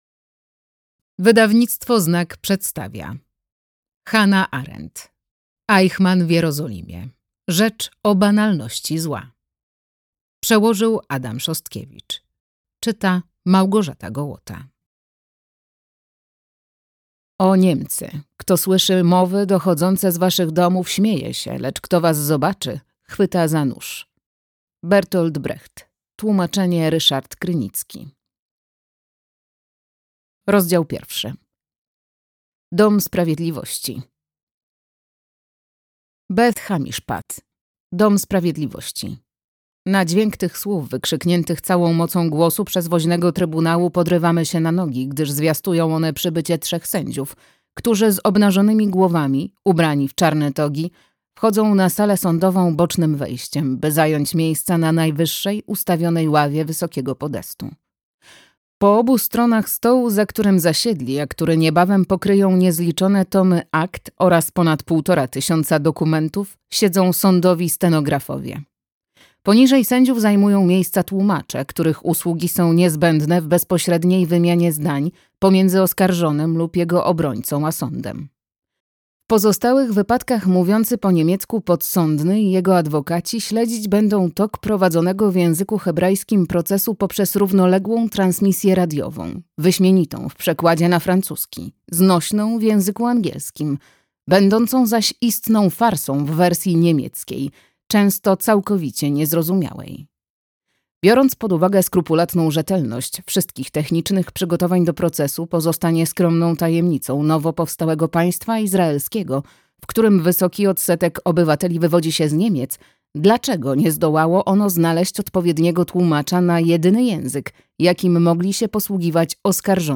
Eichmann w Jerozolimie (2022) - Hannah Arendt - audiobook